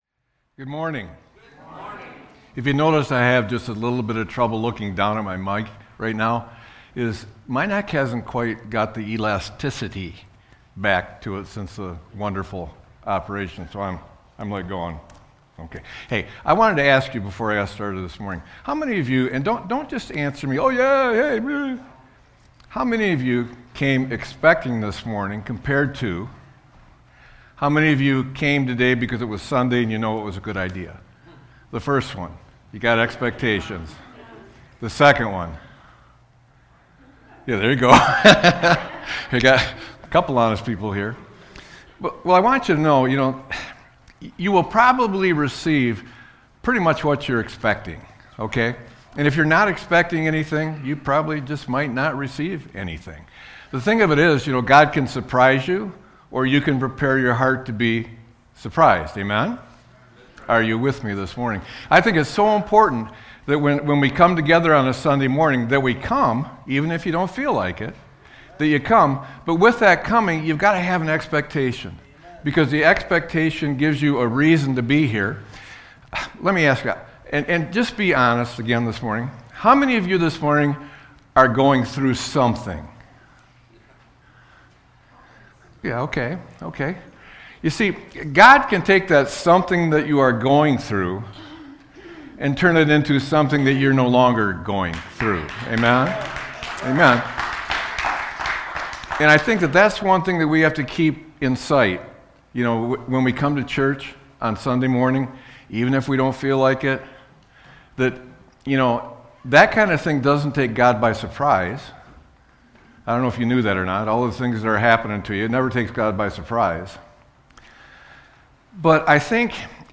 Sermon-3-15-26.mp3